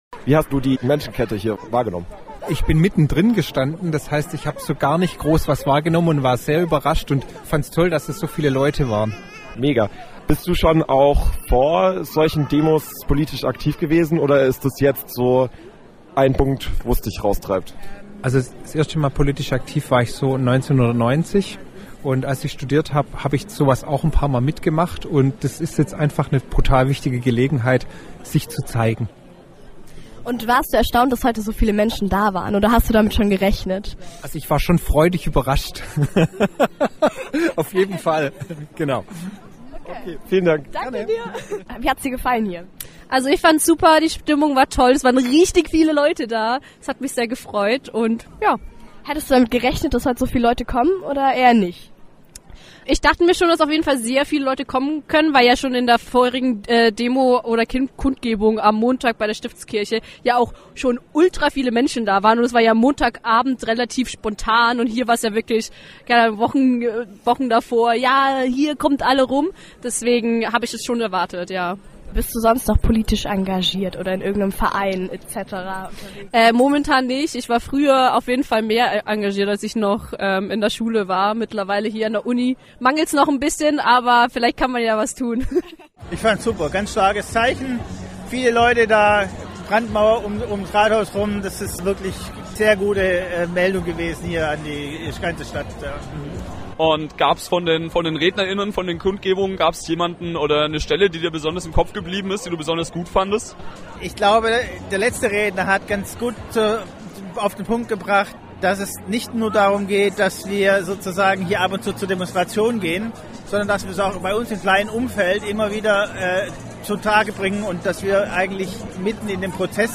Alle Reden der Kundgebung vom letzten Samstag
Am letzten Samstag, dem 27. Januar, waren mehrere Tausend Menschen auf dem Tübinger Marktplatz, um eine Brandmauer gegen rechts zu bilden und ihr Entsetzen über die bekanntgewordenen Deportationspläne kundzutun.
Hier hört Ihr alle Reden - und vorab Kurzinterviews mit Demonstrant_innen.